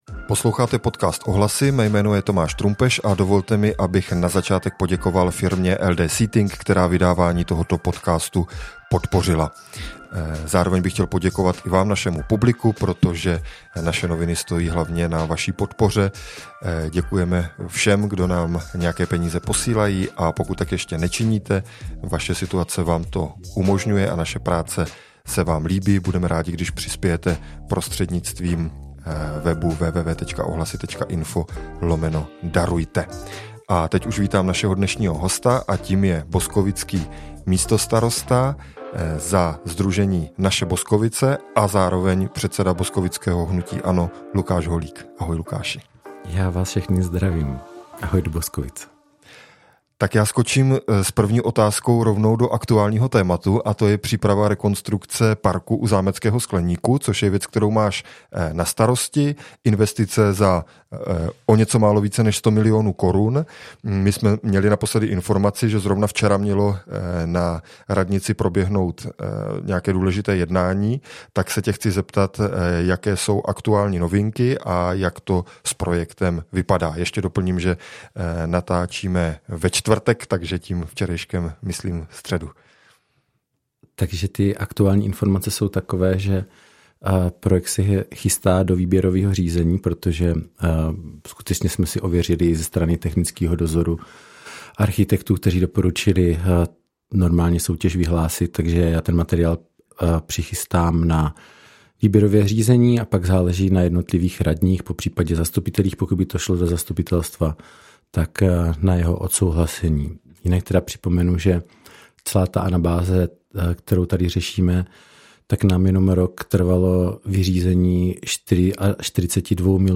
Rozhovor s místostarostou za Naše Boskovice a předsedou boskovického hnutí ANO Lukášem Holíkem. Mluvili jsme o velkých investicích, které má na starosti – o rekonstrukci parku u zámeckého skleníku a o knihovně, ale také o nemocnici, o fungování stávající koalice se Změnou22 a Piráty a o blížících se komunálních volbách.